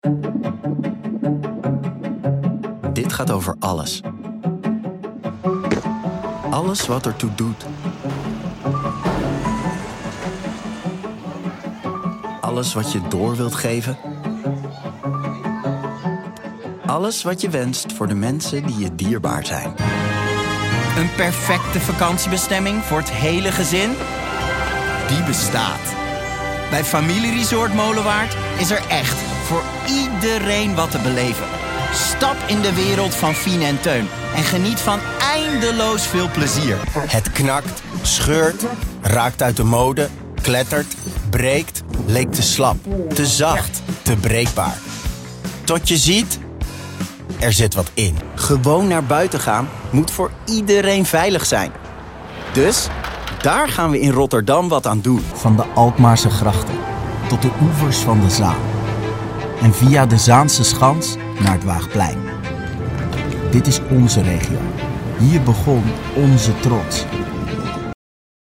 Speels, Toegankelijk, Veelzijdig, Stedelijk, Vertrouwd
Explainer